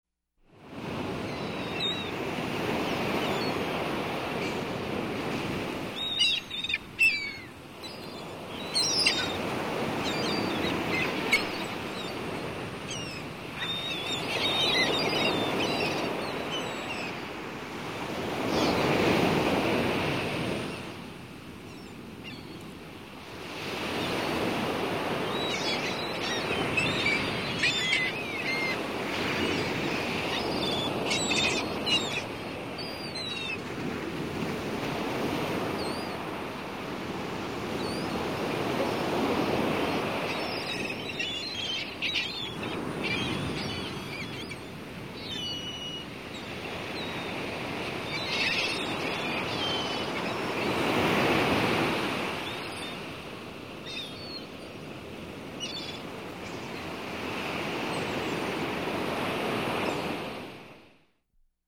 Звуки чайки
Шум волн и крики чаек